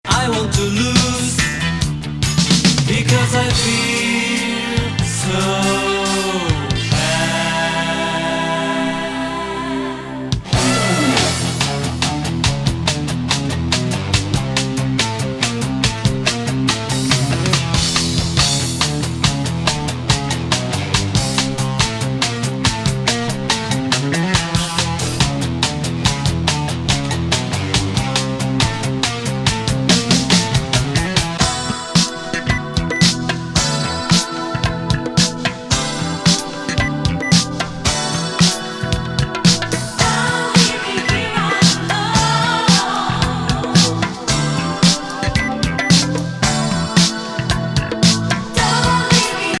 後半変調パートが短いけどキラー!!